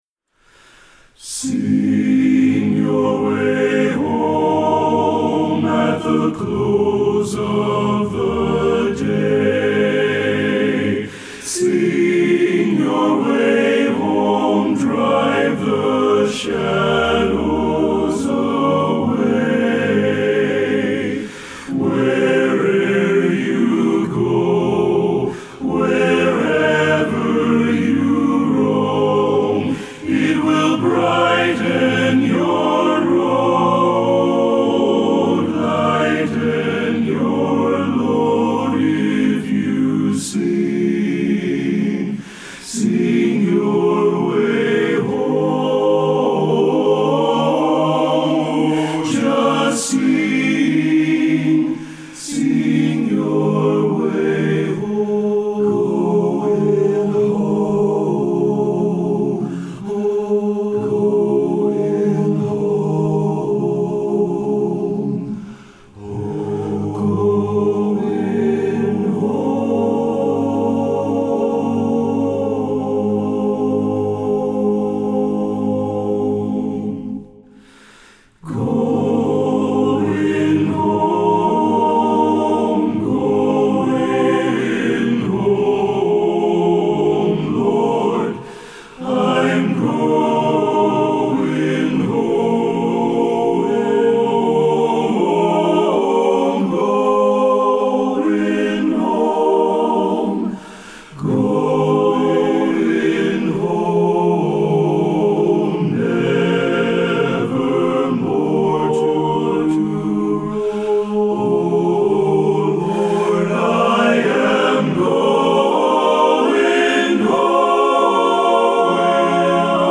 Voicing: TTBB a cappella